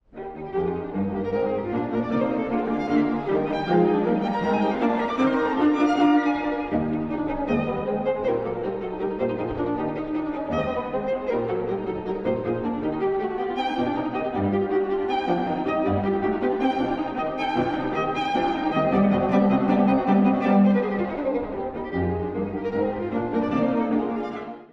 分散和音を中心とした、活気あふれる終楽章
1stVnの技巧に注目です！
アルペジオによる上昇音型がこれでもかというほど出てきます。
転調も多く、さまざまな色彩が魅力の音楽です！